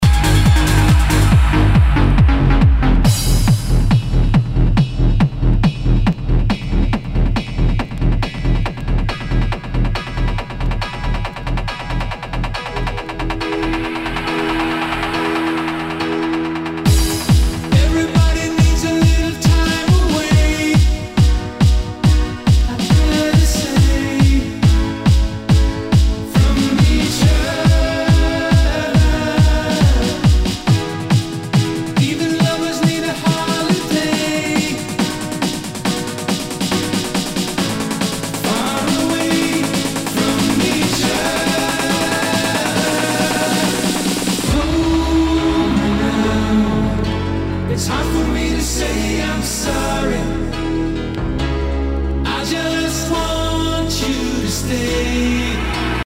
HOUSE/TECHNO/ELECTRO
ハード・ヴォーカル・トランス！